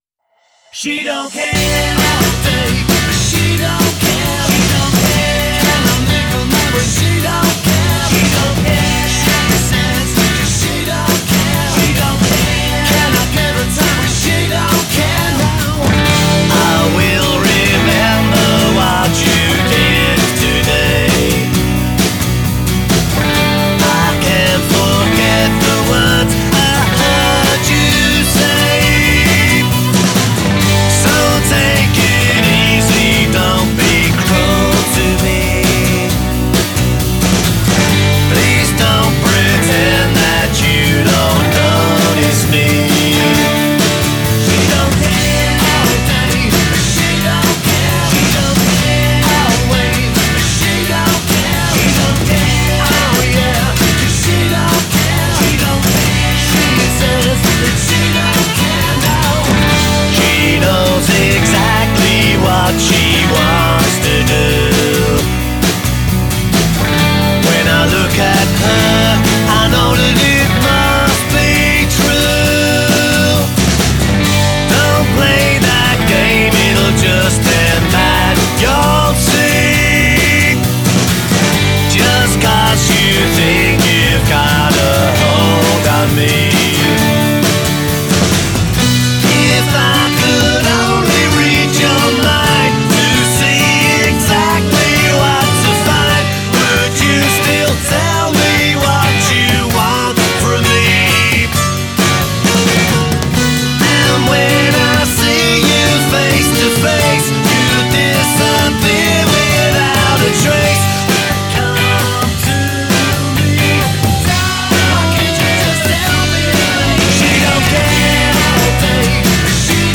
one a straight up rock and roll treatment